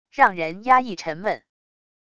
让人压抑沉闷wav音频